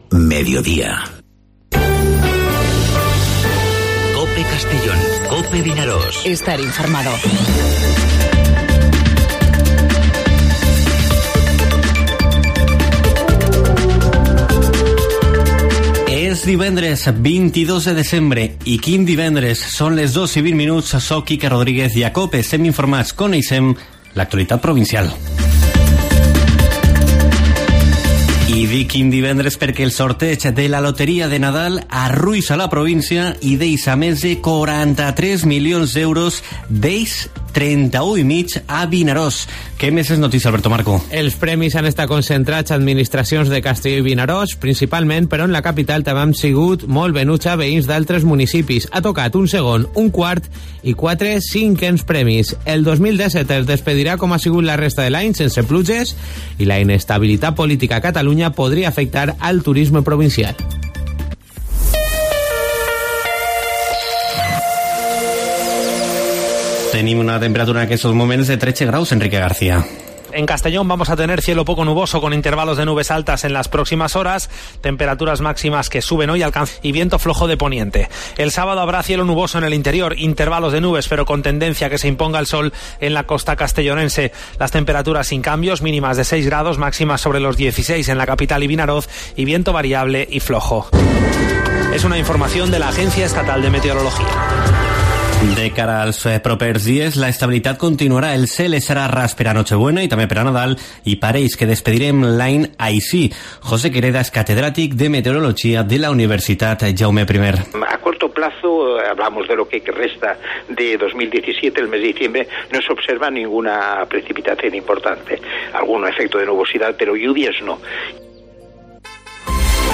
Informativo Mediodía COPE en Castellón (22/12/2017)